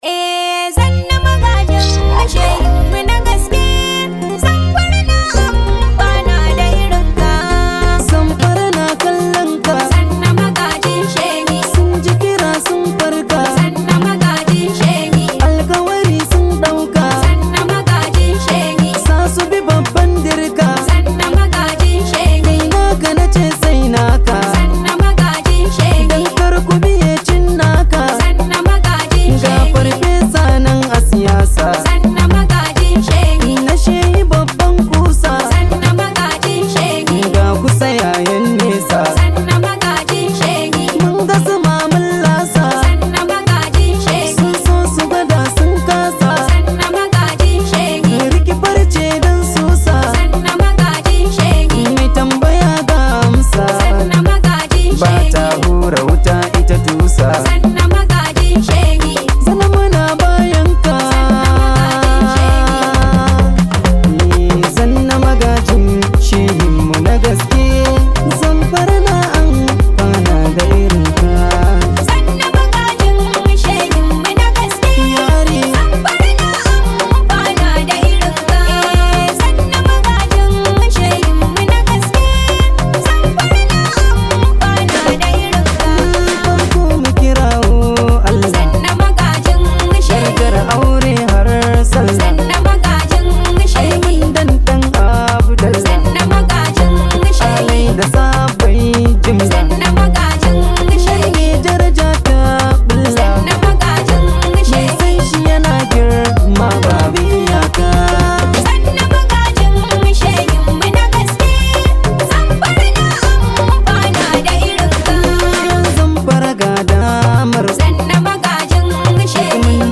much appreciated hausa song known as
high vibe hausa song